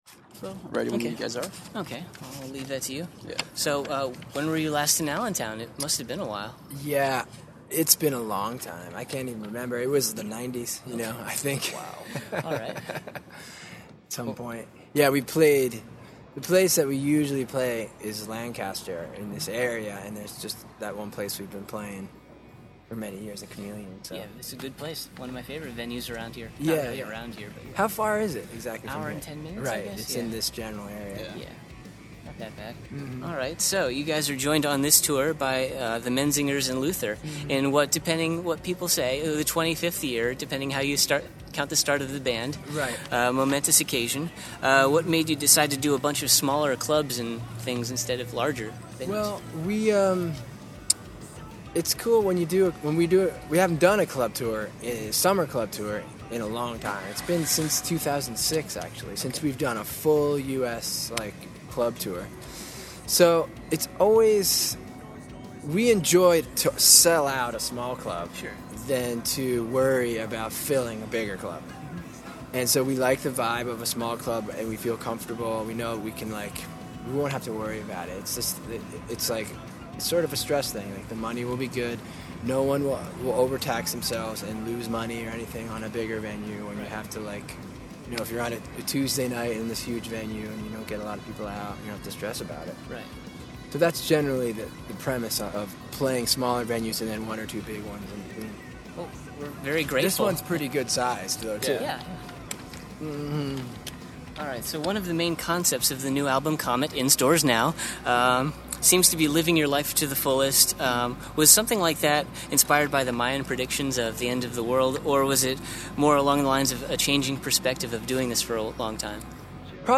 Exclusive: The Bouncing Souls Interview
We were lucky enough to to get to speak to ‘Souls’ frontman Greg Attonito towards the tail-end of their Comet club tour.
23-interview-the-bouncing-souls.mp3